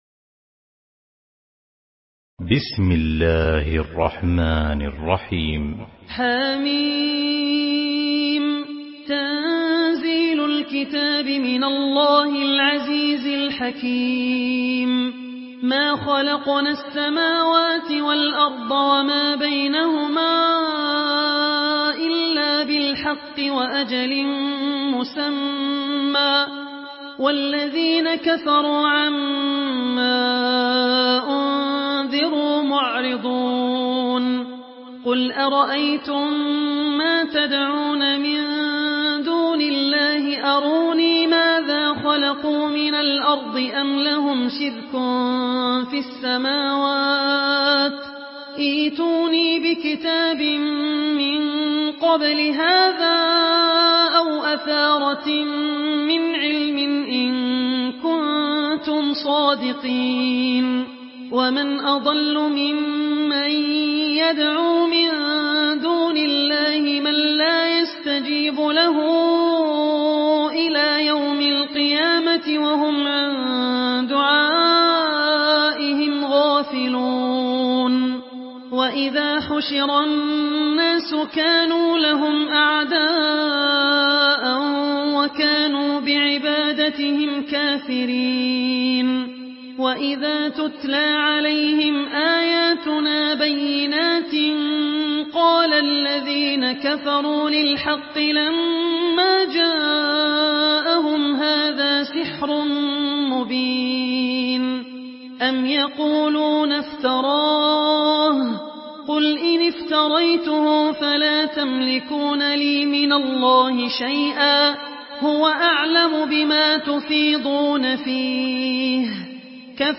Surah Al-Ahqaf MP3 in the Voice of Abdul Rahman Al Ossi in Hafs Narration
Murattal Hafs An Asim